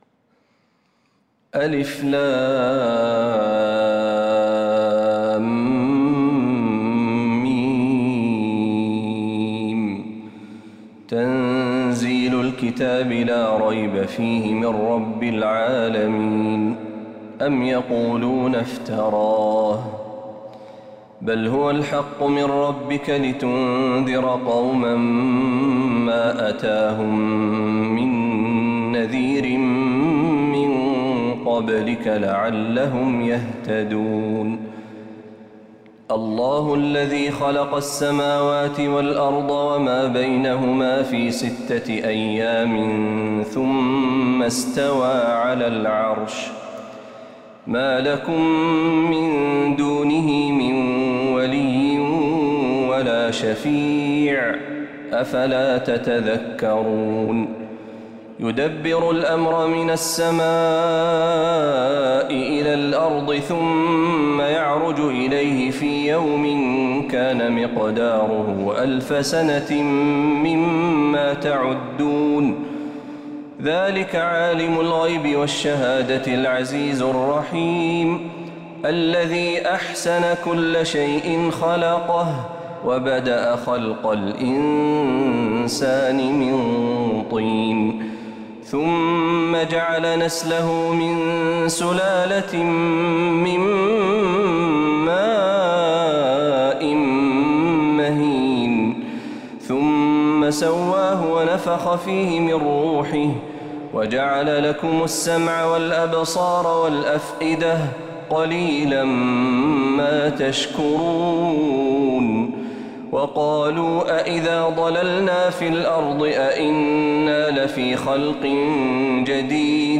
سورة السجدة كاملة من فجريات الحرم النبوي للشيخ محمد برهجي | ربيع الآخر 1446هـ > السور المكتملة للشيخ محمد برهجي من الحرم النبوي 🕌 > السور المكتملة 🕌 > المزيد - تلاوات الحرمين